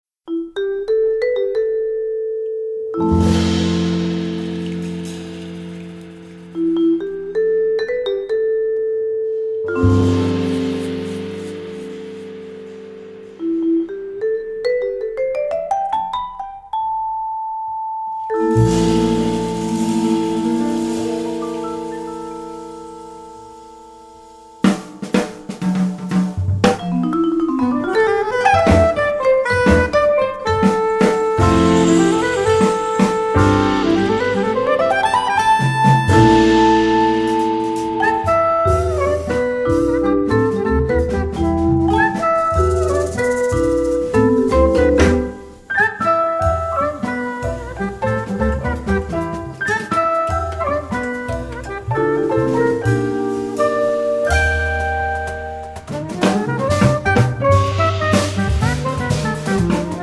vibraphone, percussion, piano